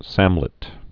(sămlĭt)